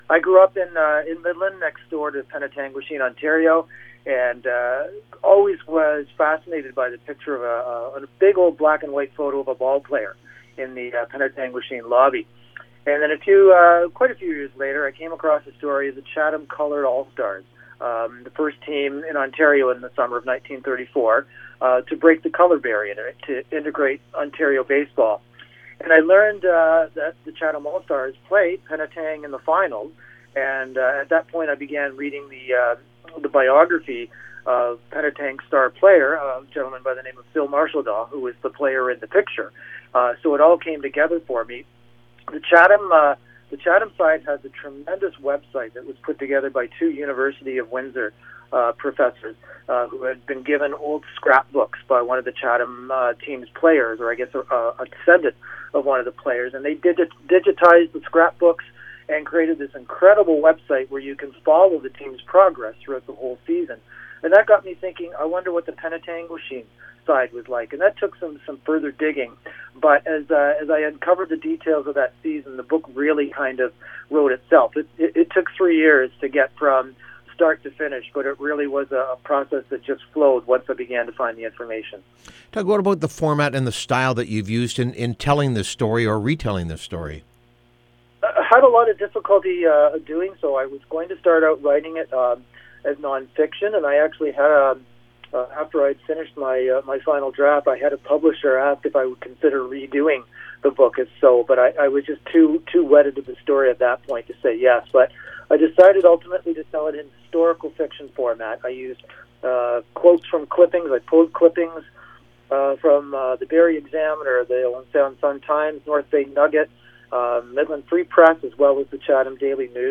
On Account Of Darkness. Interview